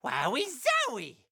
Mario during a powerup to Elephant Mario in Super Mario Bros. Wonder.